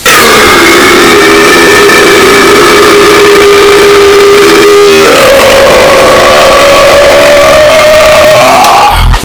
Radio Noises Lol Bouton sonore
Play and download the Radio Noises Lol sound effect buttons instantly!